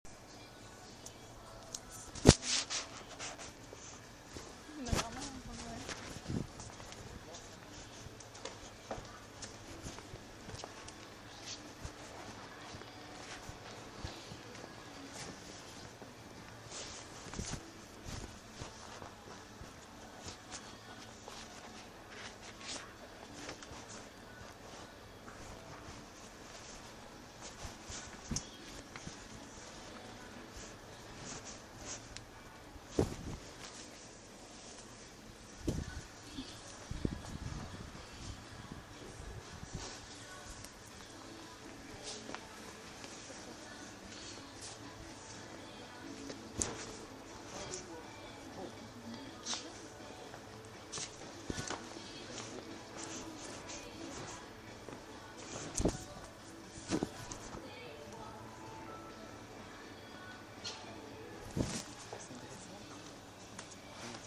carrefour de Rilhac Rancon
Musique
Share Facebook X Next Devant l'entrée principale des galerie.